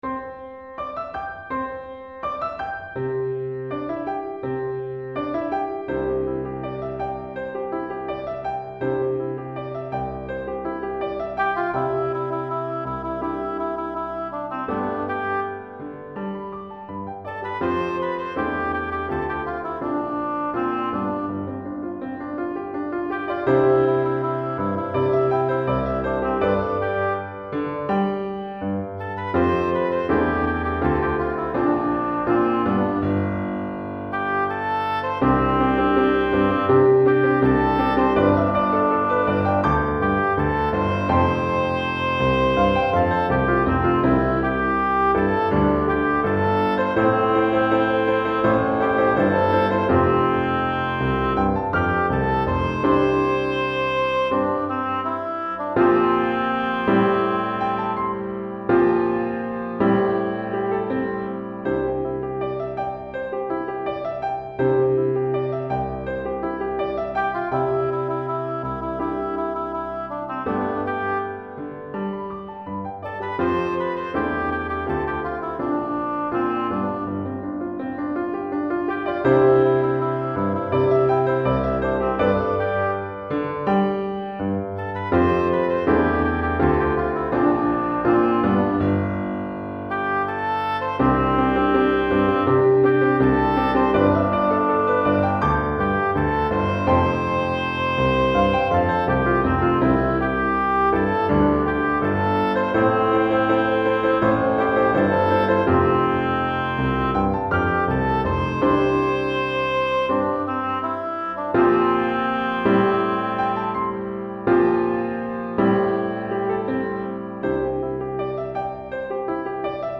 Children's Choeur 1 Piano